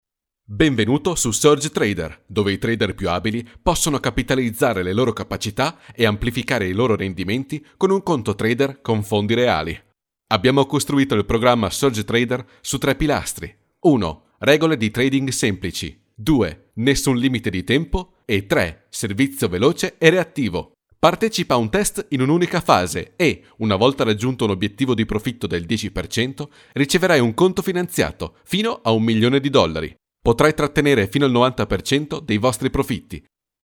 特点：轻快活力 大气浑厚 稳重磁性 激情力度 成熟厚重
意大利男女样音